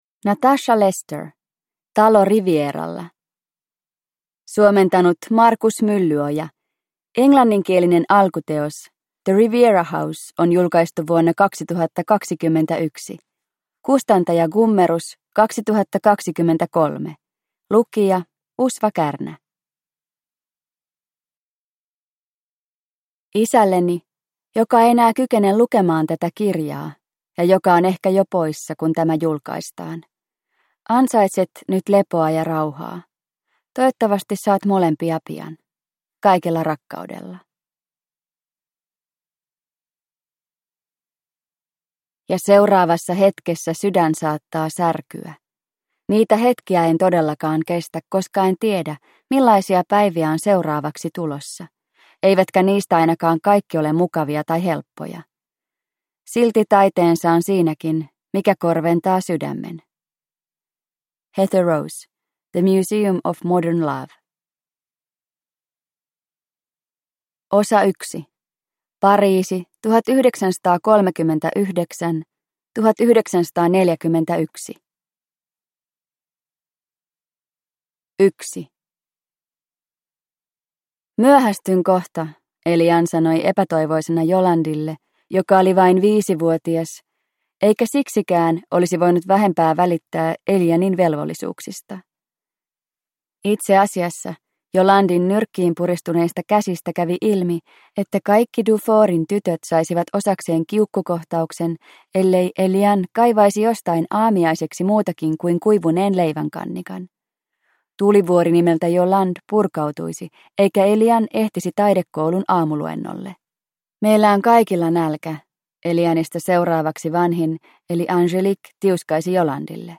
Talo Rivieralla – Ljudbok – Laddas ner